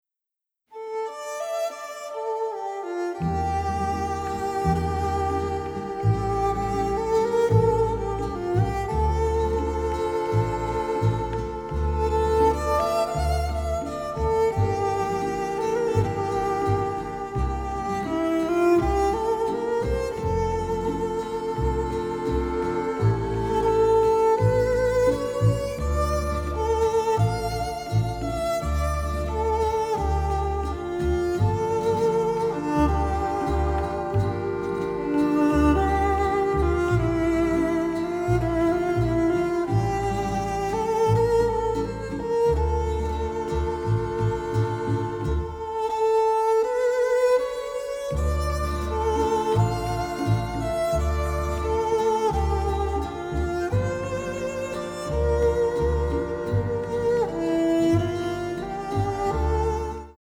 The Soundtrack Album (stereo)